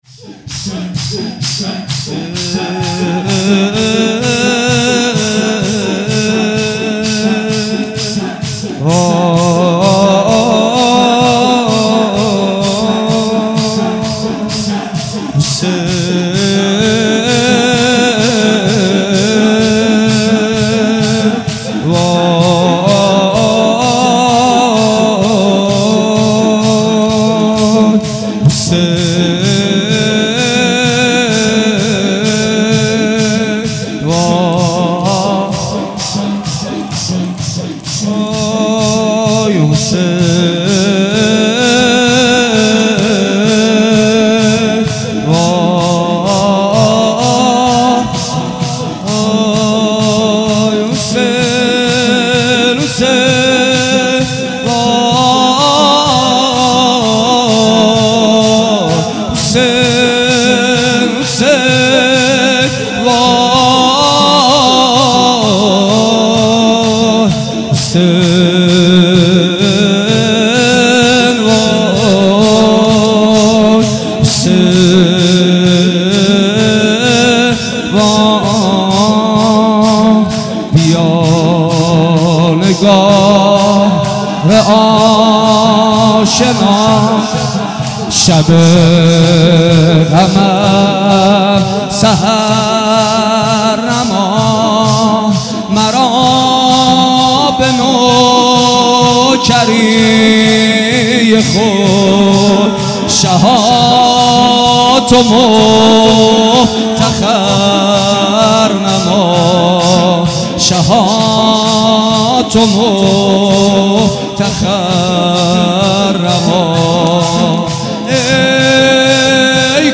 بیا نگار آشنا شب غمم سحر نما | شور | حضرت امام حسین علیه السلام
شب دوم محرم الحرام 1393
محمدحسین پویانفر